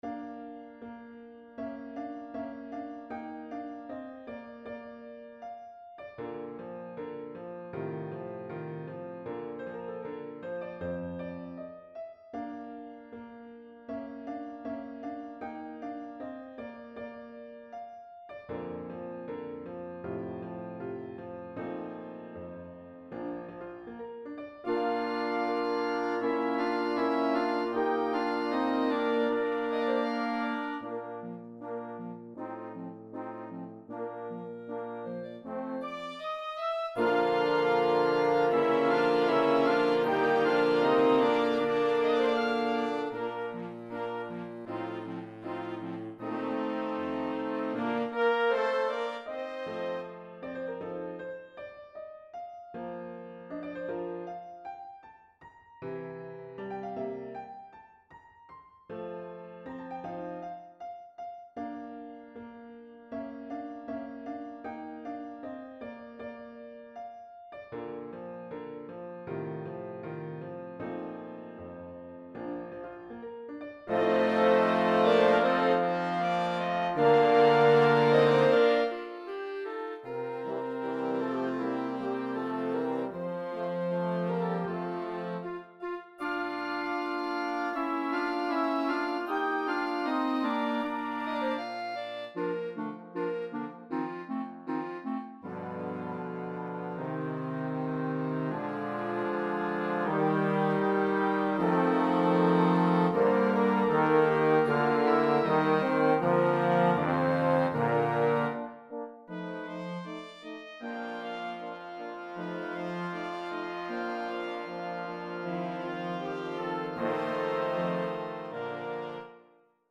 Voicing: Concert Band and Piano